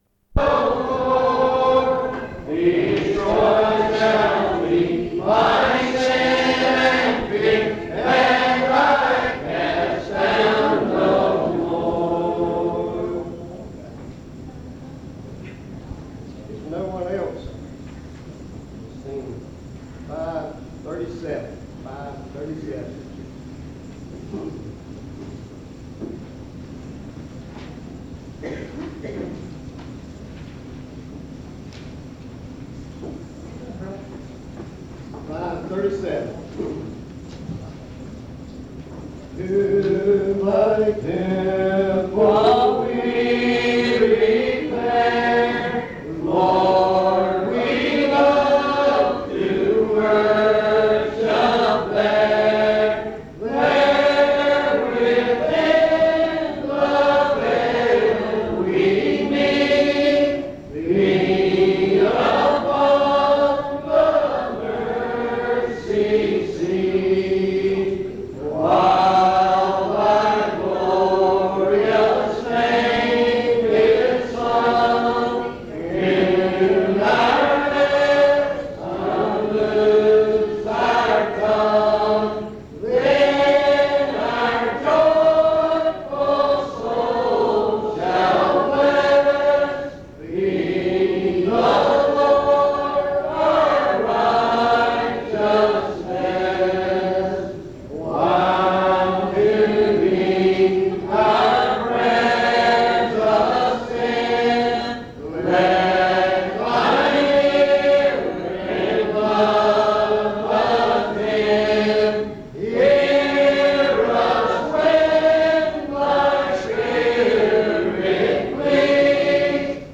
Location Churchland (N.C.) Davidson County (N.C.)